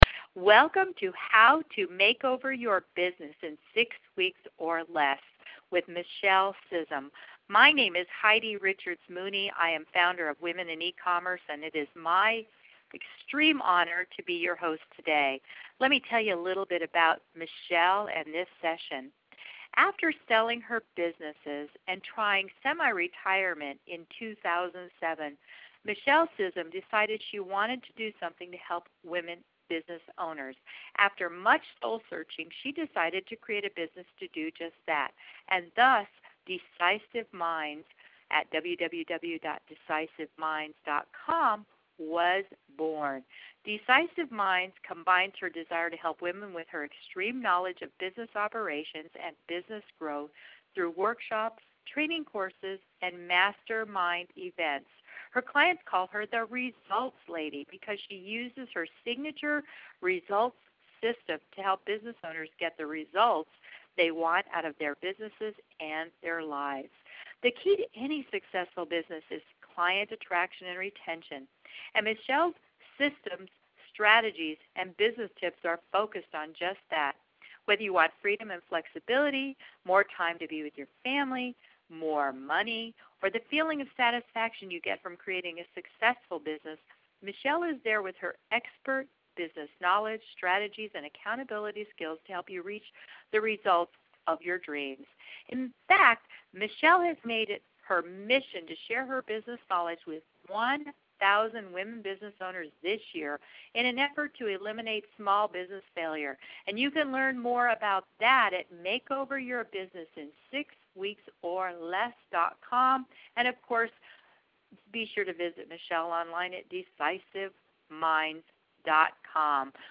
Yesterday I had the opportunity to interview